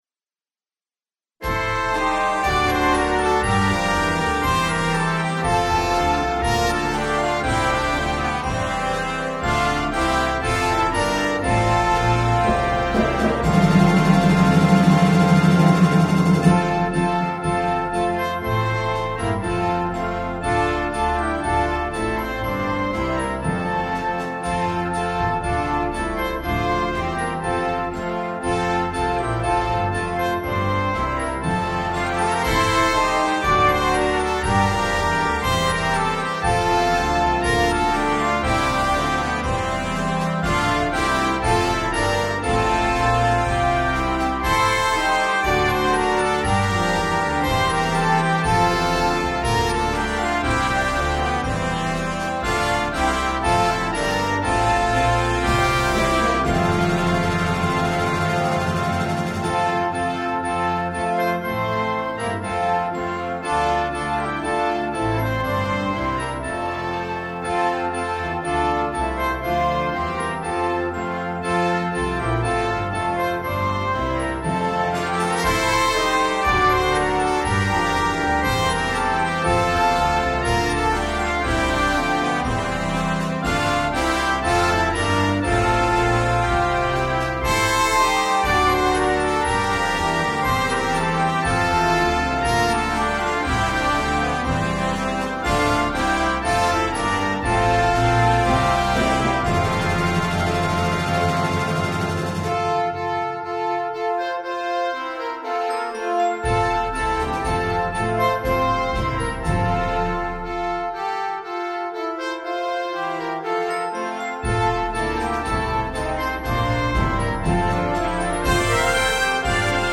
There is a descant in the final verse.
The MP3 was recorded with NotePerformer 3.
Christmas